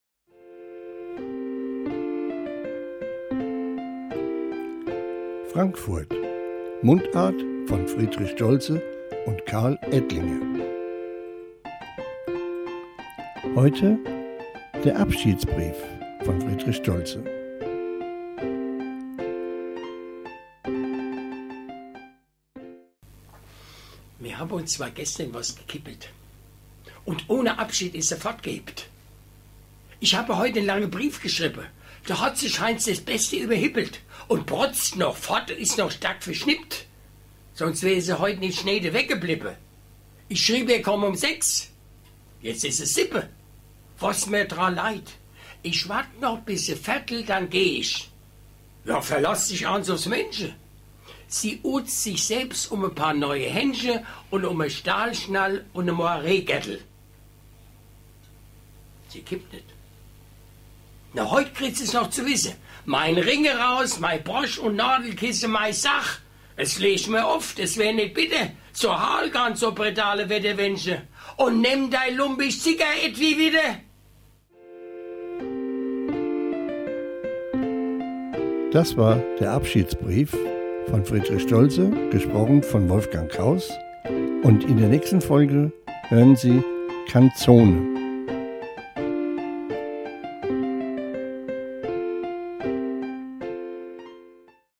Mundart von Friedrich Stoltze "Abschiedsbrief"
Gedicht: Abschiedsbrief